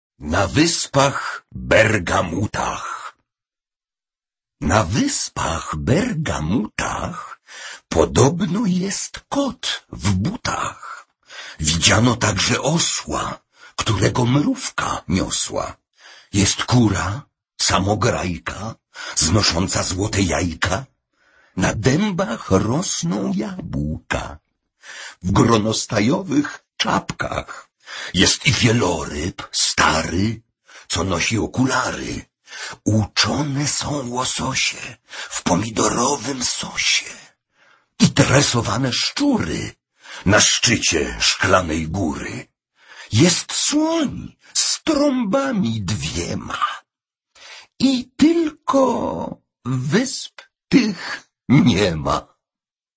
Давайте, наконец, соберём все его строчки вместе и в замечательном исполнении Петра Фрончевского послушаем стихотворение целиком: